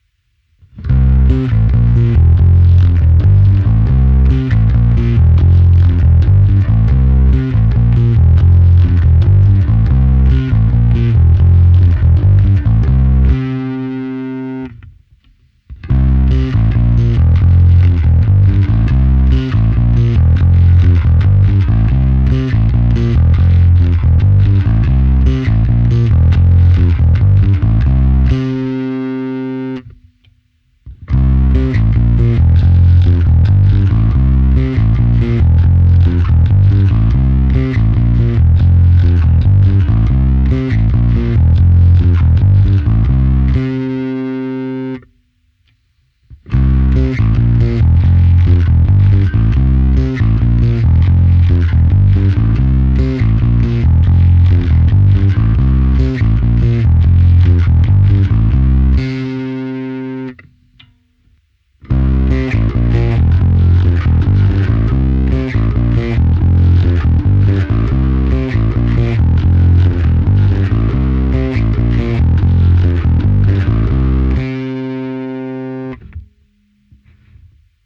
Bonusové nahrávky se simulací aparátu ve stejném pořadí jako výše (1-5)
Ukázka čistého zvuku